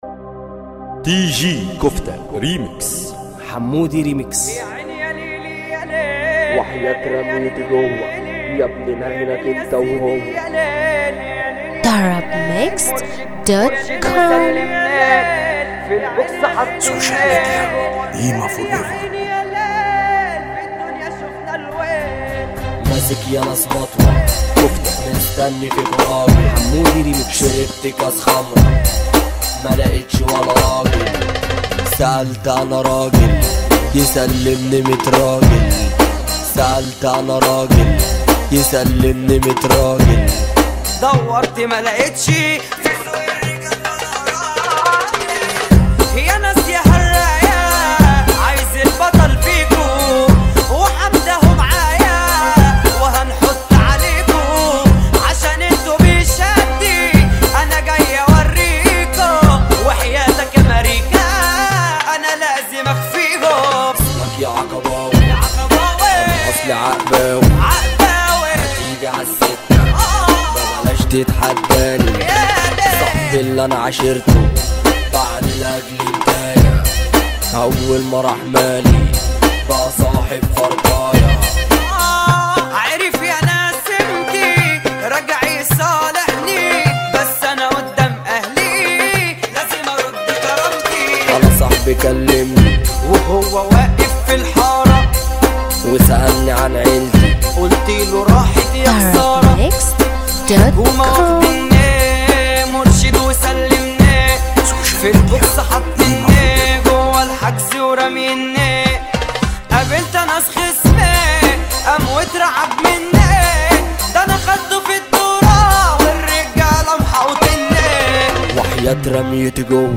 مهرجان